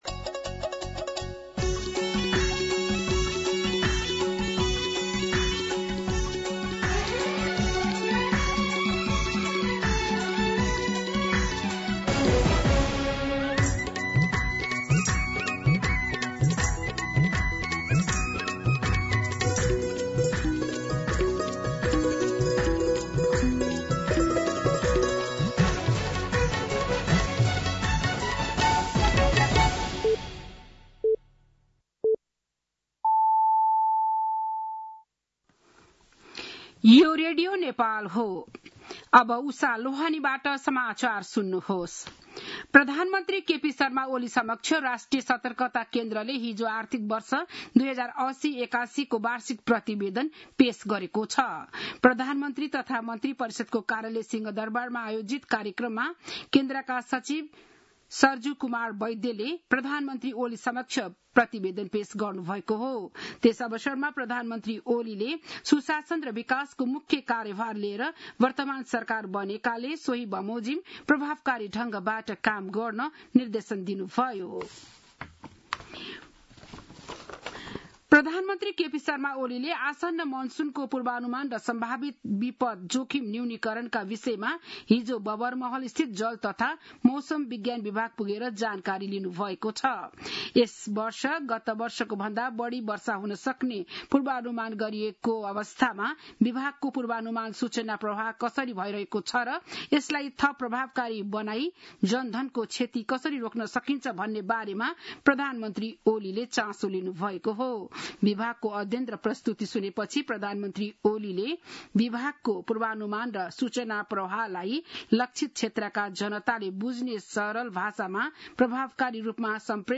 बिहान ११ बजेको नेपाली समाचार : १४ जेठ , २०८२